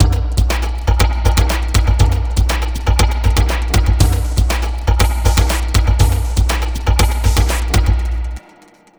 Astro 1 Drumz.wav